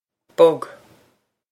bog bug
This is an approximate phonetic pronunciation of the phrase.